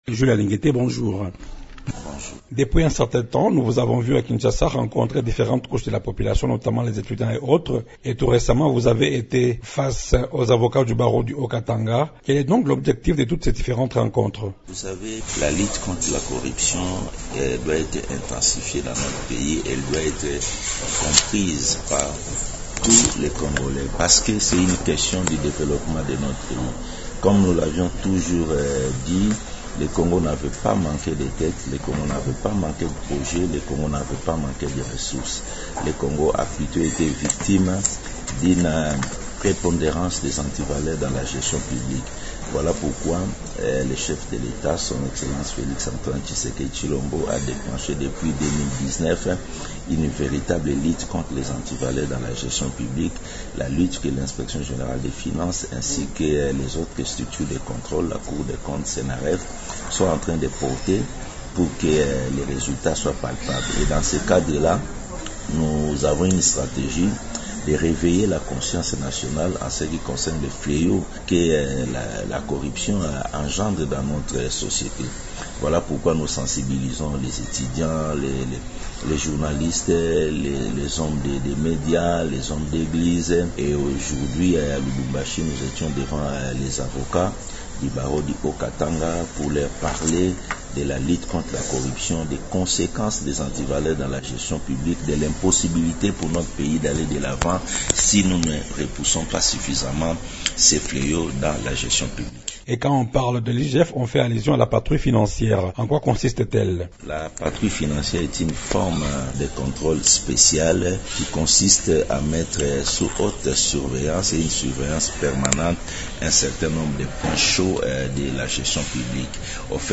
interview_avec_jules_alingete_web_0.mp3